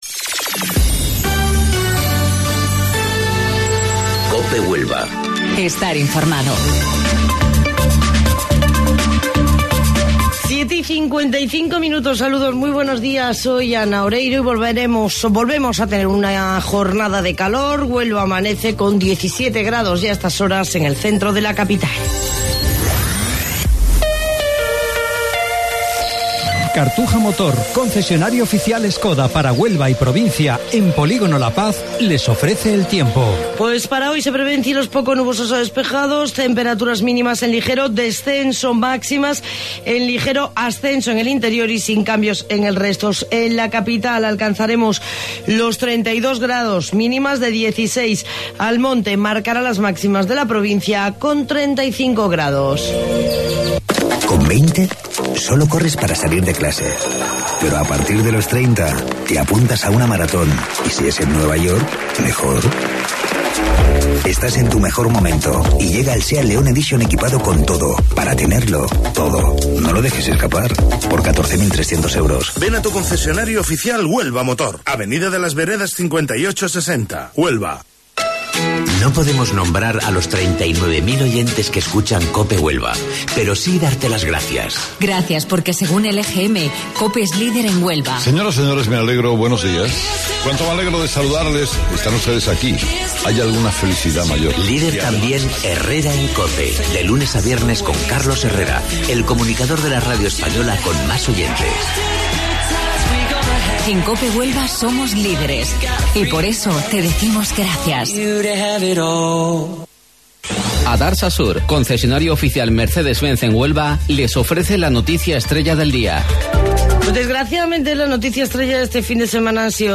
AUDIO: Informativo Local 07:55 del 13 de Mayo